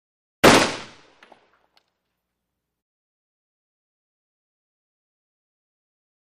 M-1 Rifle; Single Shot With Casings Drop On Cement And Movement.